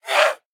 attack_11.ogg